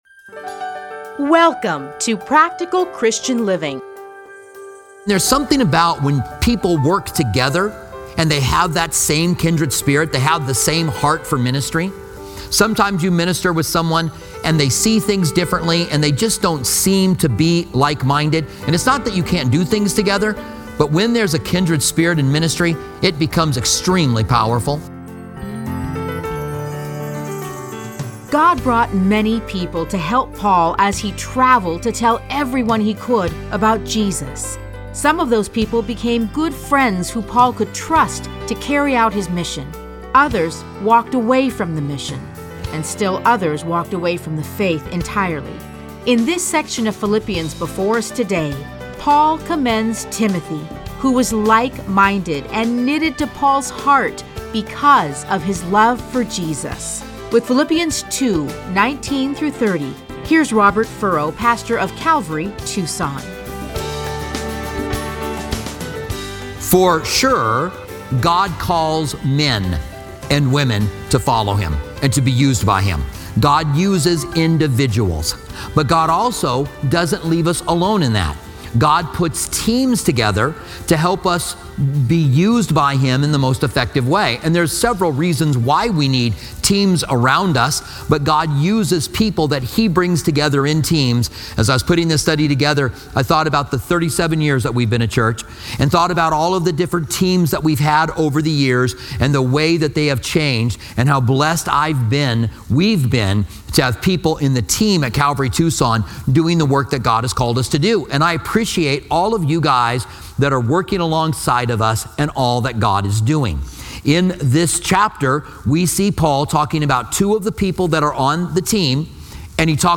Listen to a teaching from A Study in Philippians 2:19-30.